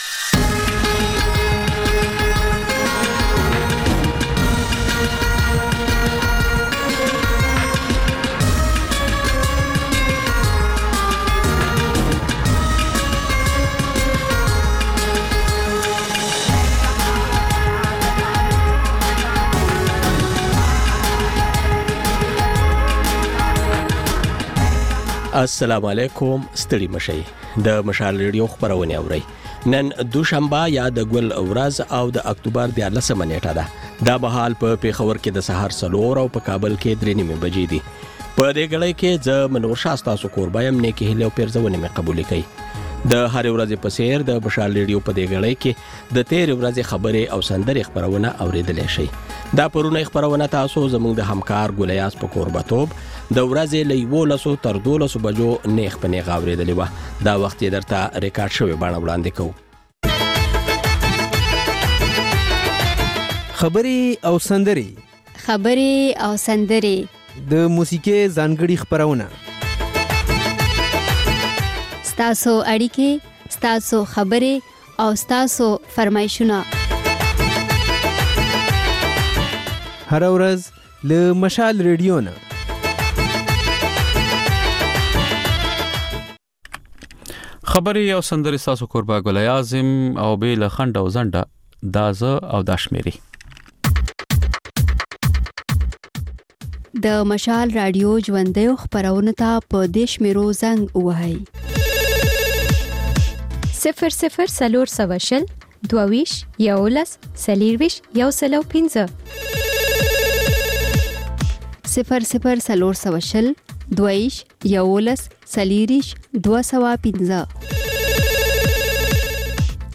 دلته د خبرې او سندرې خپرونې تکرار اورئ. په دې خپرونه کې له اورېدونکو سره خبرې کېږي، د هغوی پیغامونه خپرېږي او د هغوی د سندرو فرمایشونه پوره کېږي.